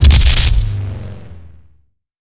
gauss_siege_fire1.wav